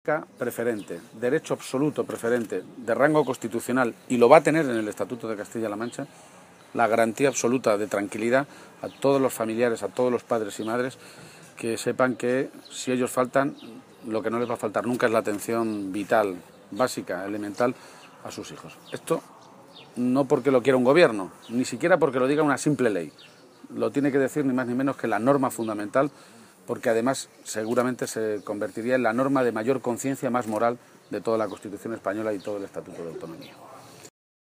García-Page se pronunciaba de esta manera esta mañana, en Yepes, en la provincia de Toledo, donde tiene su sede y su centro de Atención AMAFI, una Asociación para el Cuidado Integral de las personas con discapacidad intelectual.
Allí ha recorrido las instalaciones acompañado por el equipo directivo y, tras finalizar la visita y en una atención a los medios de comunicación, ha sostenido que centros de este tipo «hacen que una salga más moralizado que cuando entró».